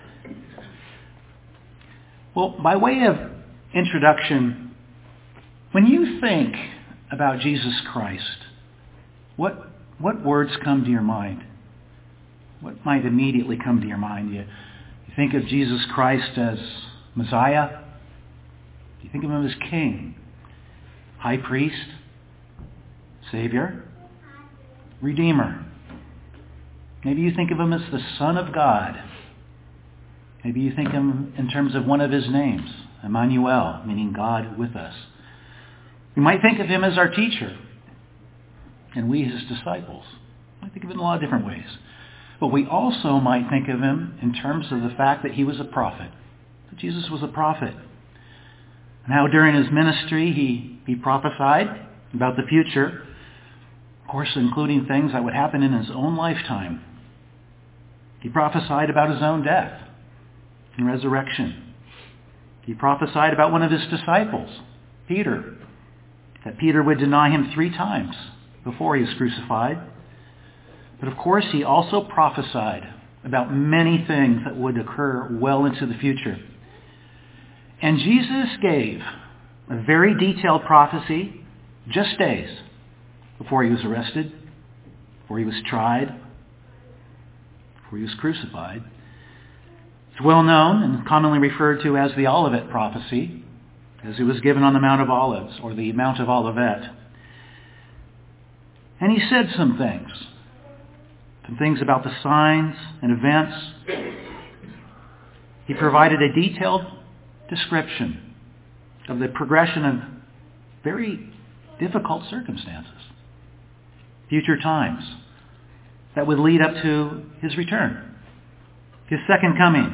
In Matthew 24:12 Jesus prophesied of times of lawlessness and how the love of many will grow cold. The sermon will focus on this particular test of God's people and how to overcome it.